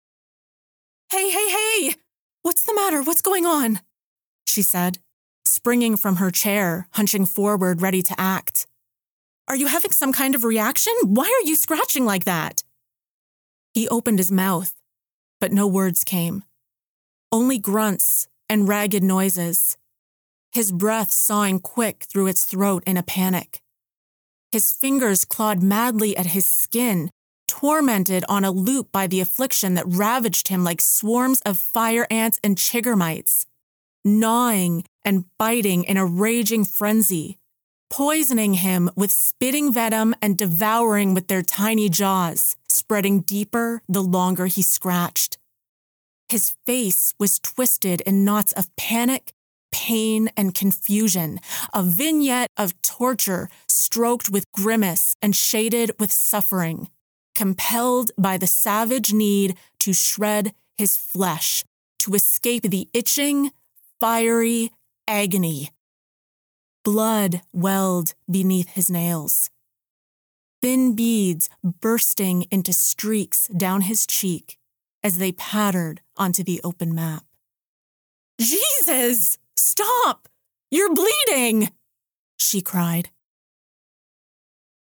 I have a professional sound treated recording studio with industry standard microphones, equipment, and recording / audio editing software.